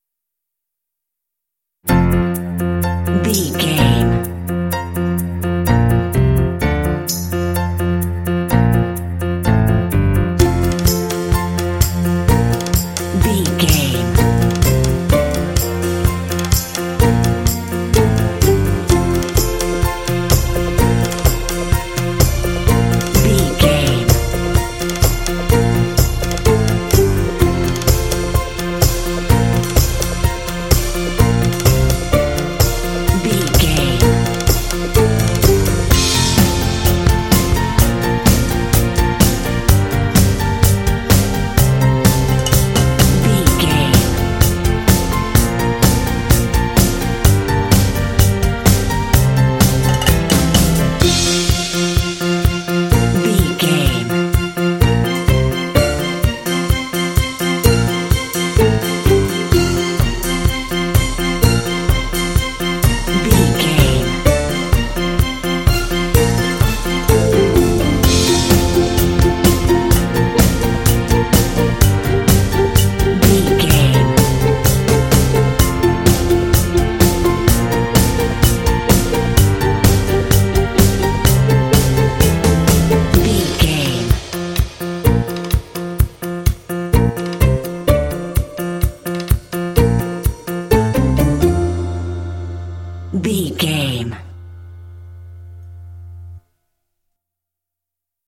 Ionian/Major
hopeful
optimistic
uplifting
piano
percussion
drums
strings
contemporary underscore
alternative rock
indie